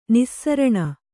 ♪ nissaraṇa